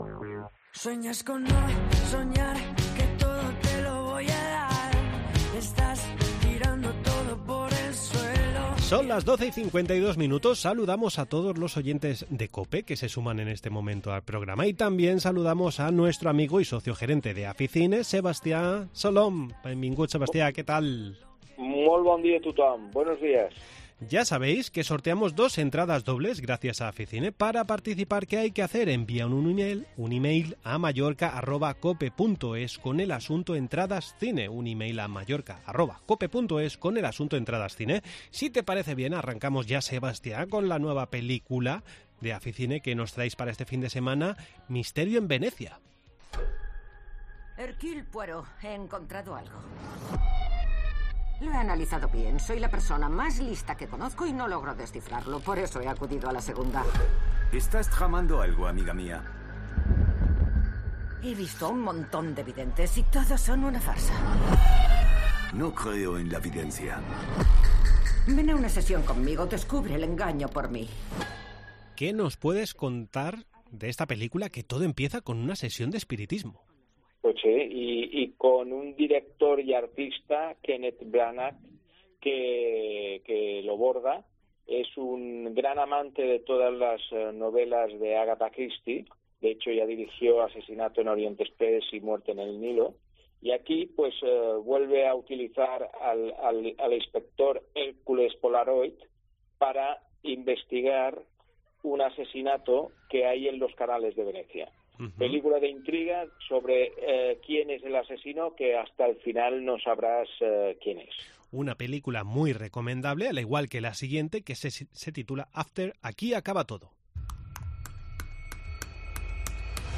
. Entrevista en La Mañana en COPE Más Mallorca, viernes 15 septiembre de 2023.